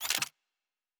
Weapon 05 Foley 1 (Laser).wav